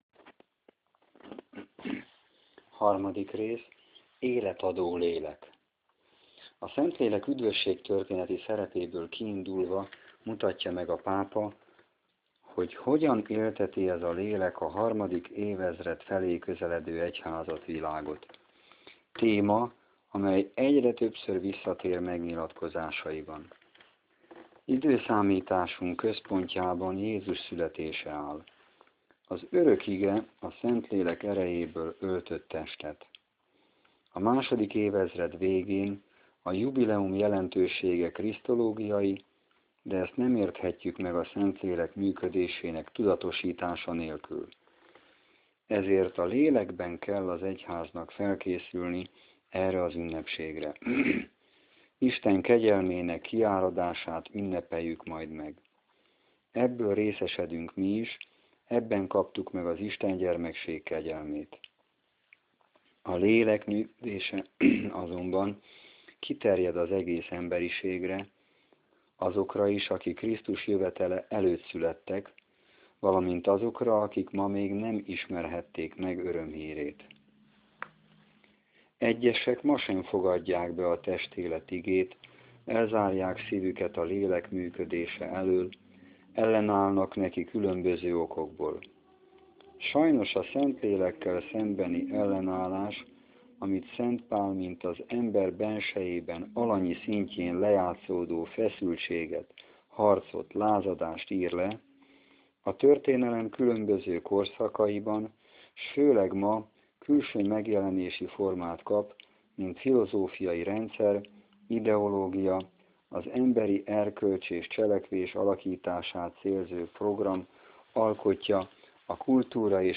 febr 19, 2014 | Könyv, hangoskönyv |